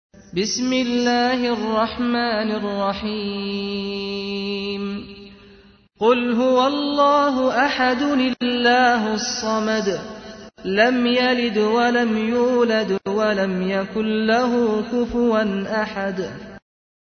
تحميل : 112. سورة الإخلاص / القارئ سعد الغامدي / القرآن الكريم / موقع يا حسين